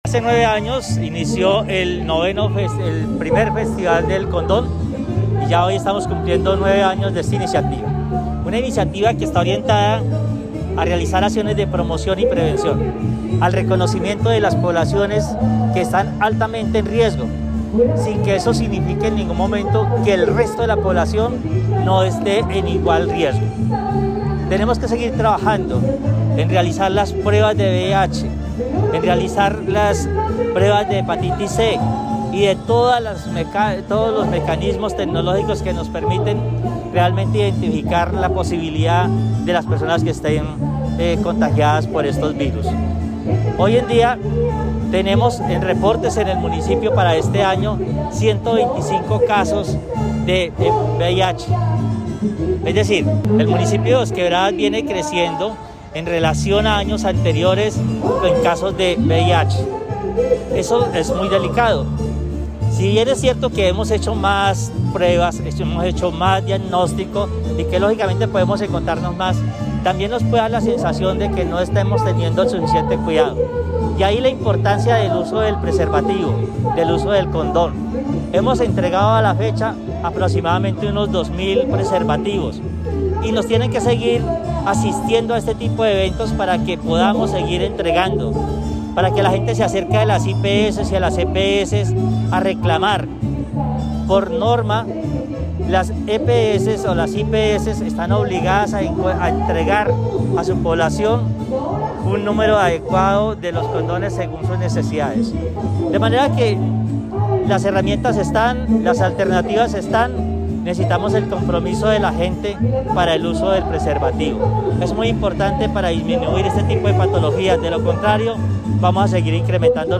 Escuchar Audio: Secretario de Salud y Seguridad Social, Ricardo Montilla Bolaños.
Comunicado_818_Audio_1_Secretario_de_Salud_Ricardo_Montilla.mp3